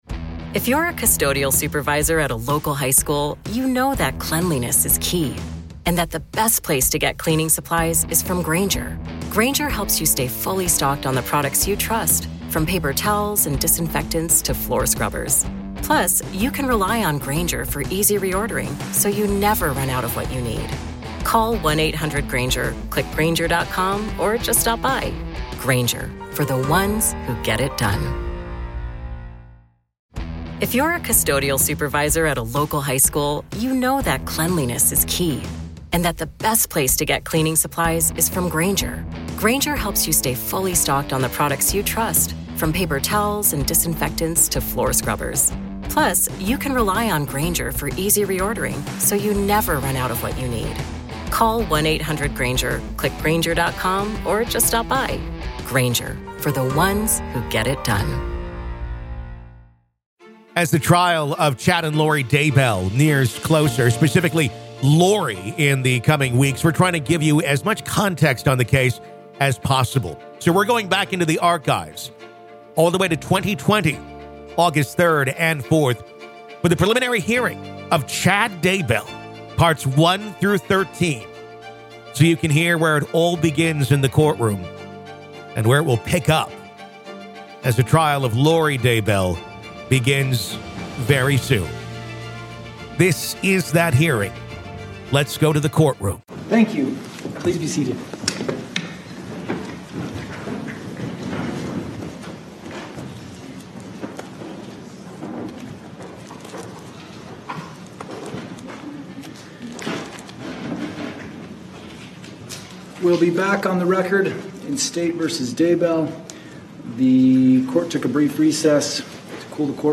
Listen To The Full Preliminary Hearing Of Chad Daybell, Part 8